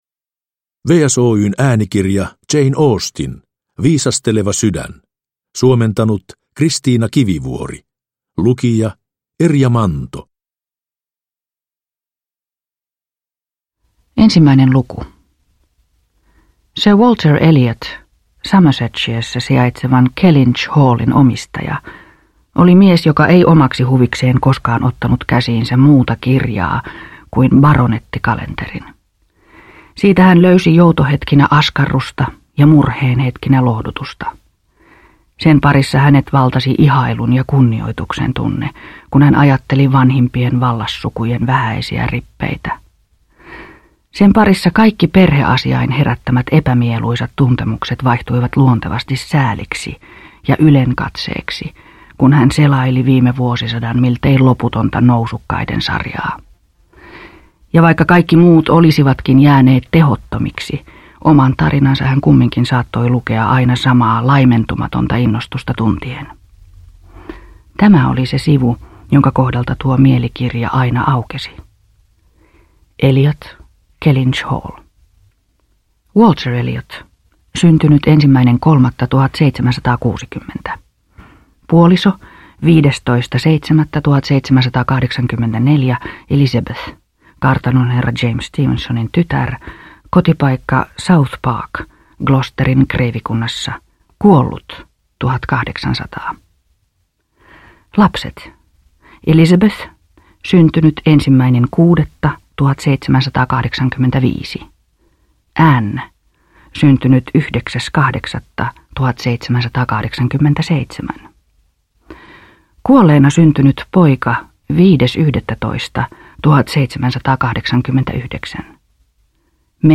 Viisasteleva sydän – Ljudbok – Laddas ner